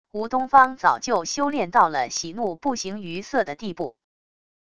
吴东方早就修炼到了喜怒不形于色的地步wav音频生成系统WAV Audio Player